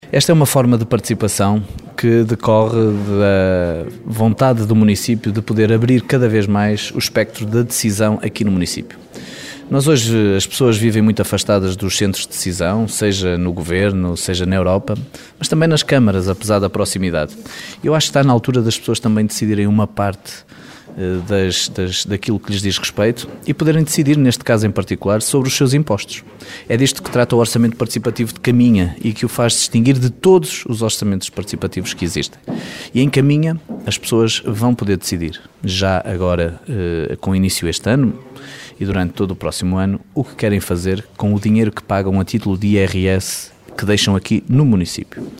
Em causa está um modelo inovador já que o valor arrecadado em termos de participação variável no IRS será investido de acordo com o que for a decisão das pessoas, como refere o autarca Miguel Alves